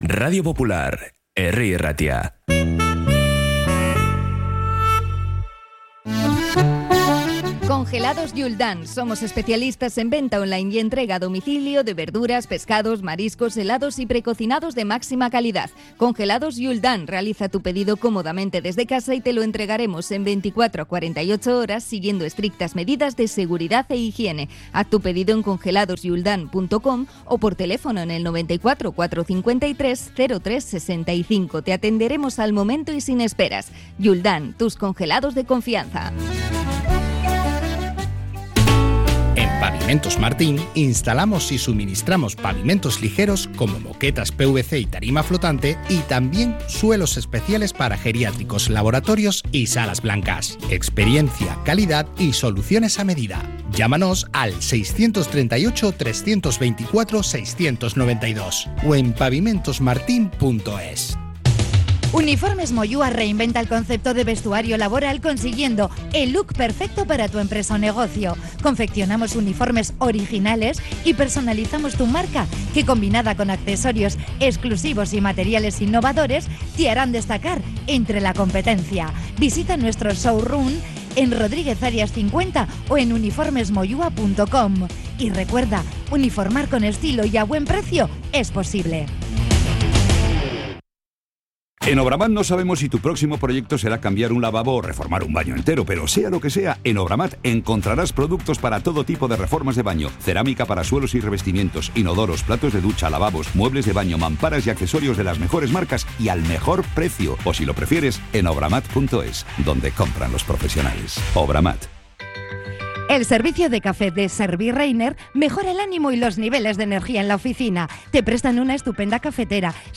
Desde Bilbao Urban Sagardotegi hemos analizado, como cada viernes, toda la actualidad rojiblanca